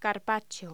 Locución: Carpaccio
voz